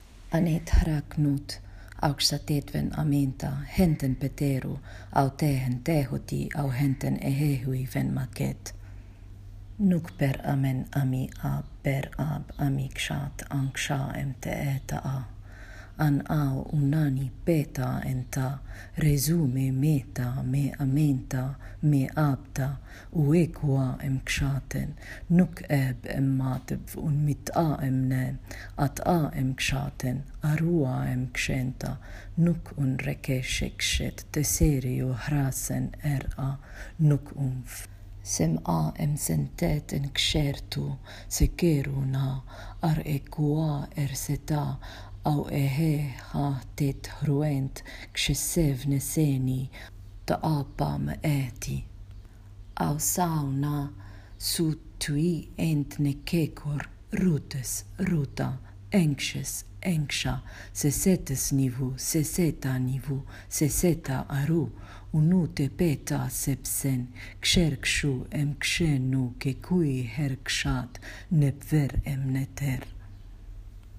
Reading of the prayer: